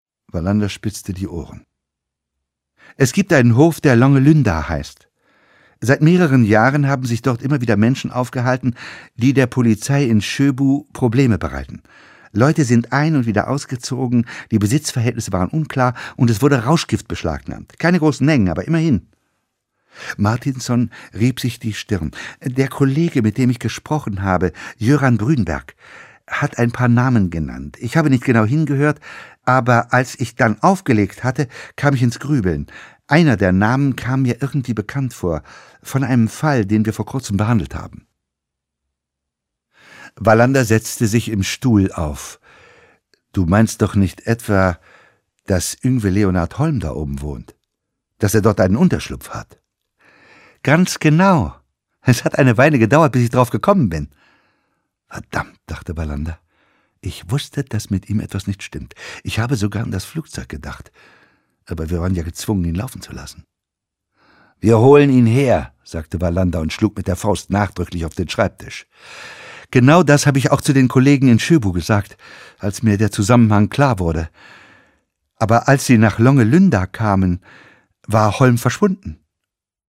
Die Pyramide (Ein Kurt-Wallander-Krimi) 4 CDs Henning Mankell (Autor) Ulrich Pleitgen (Sprecher) Audio-CD 2007 | 2.